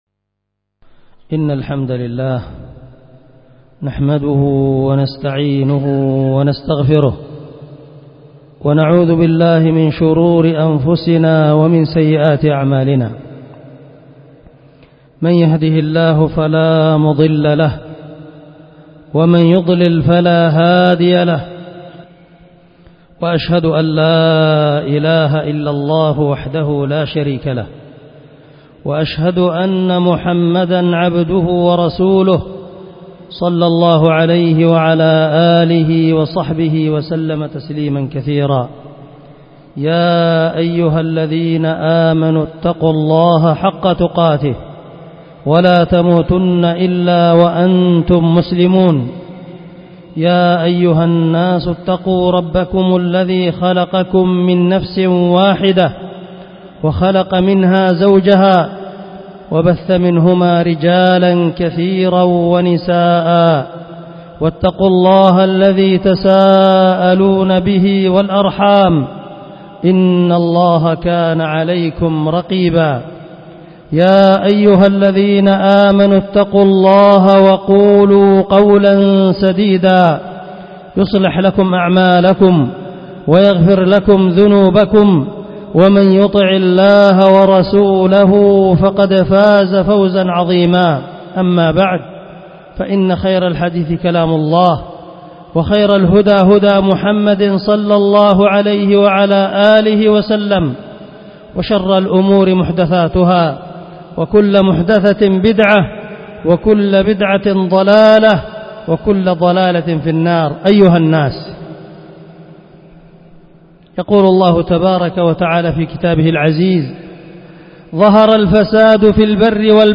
خطبة جمعة بعنوان قوله تعالى ظهر الفساد في البر والبحر بما كسبت أيدي الناس بتارخ 1442ربيع آخر 26